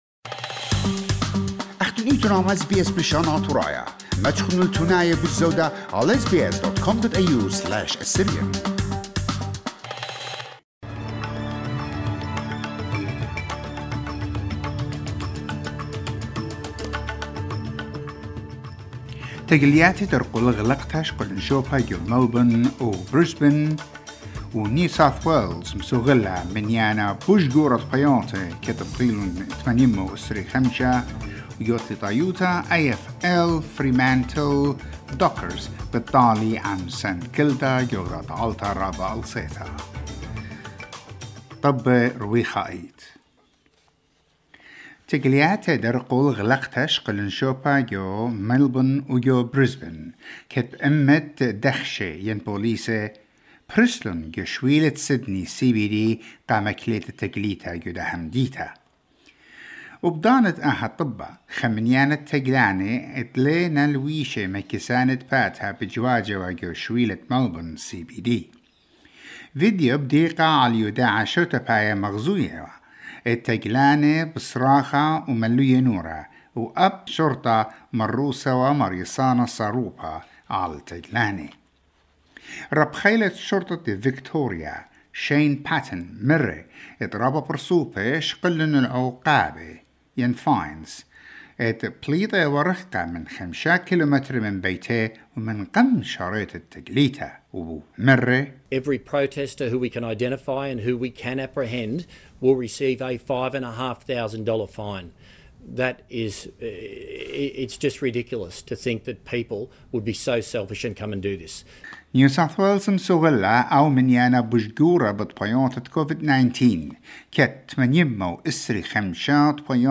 SBS News Bulletin in Assyrian for Saturday 21 August 2021